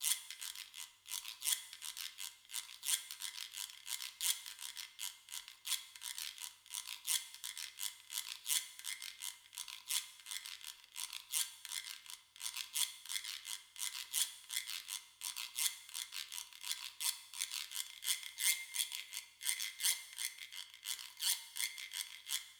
Track 1 “Reco Reco”
BDL_ChildsIntroJazz_Track-1_Reco-Reco.wav